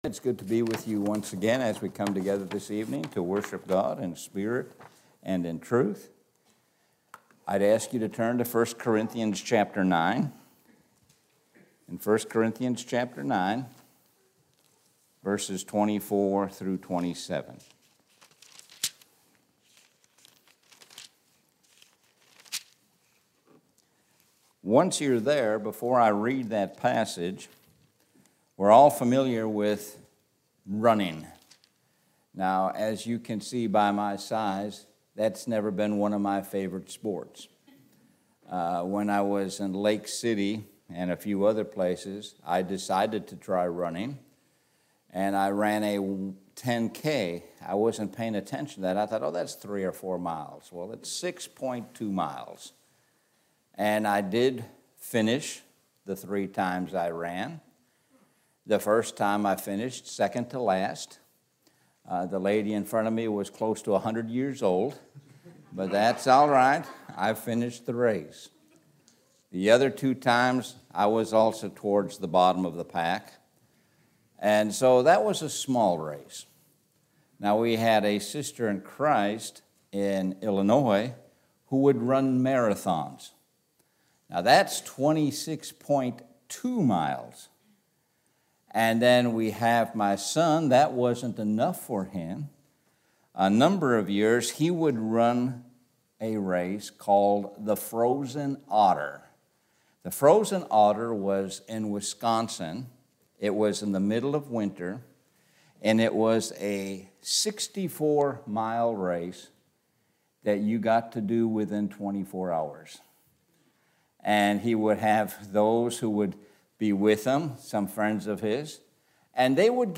Sun PM Sermon – Keep running